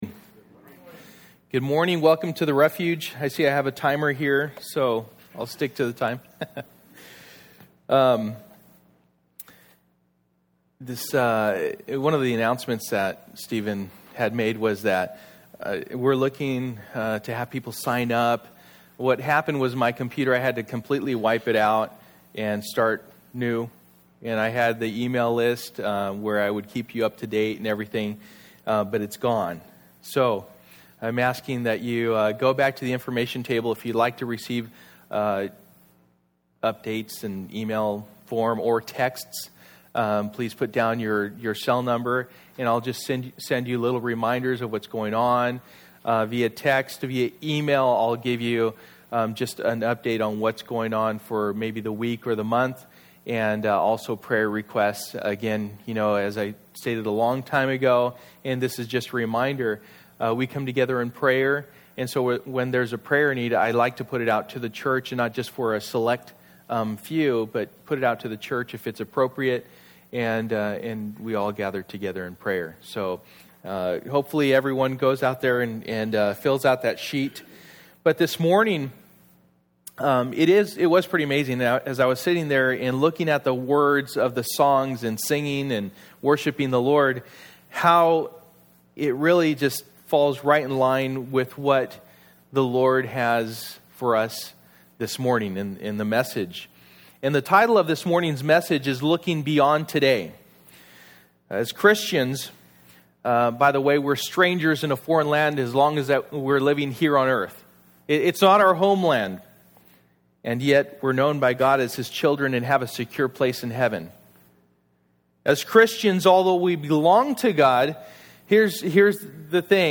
Stand Fast Passage: 1 Peter 1:1-25 Service: Sunday Morning %todo_render% « Serving 101 Stand Fast